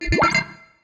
UIBeep_Access Granted.wav